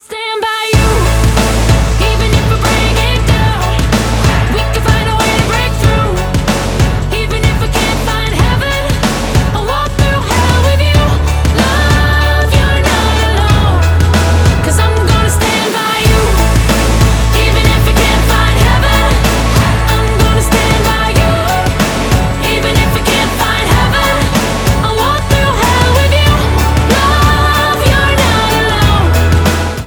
• Pop Ringtones